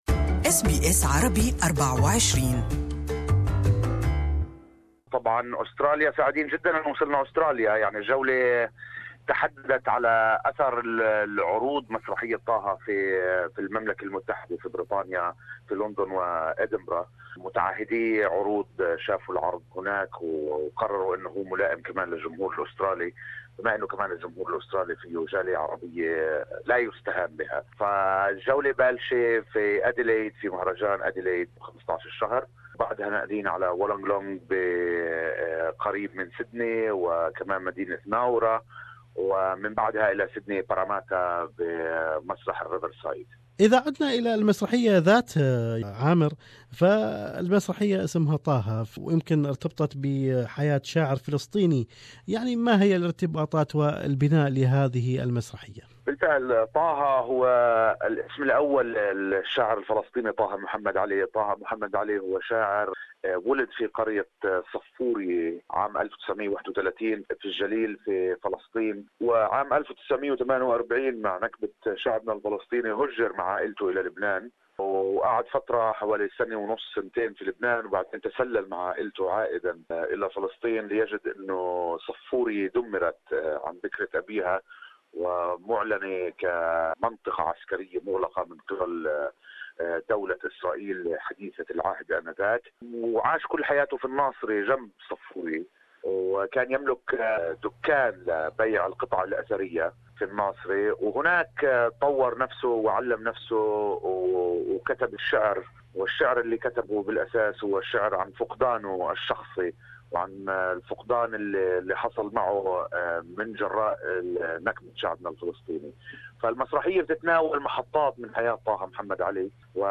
More is in this interview..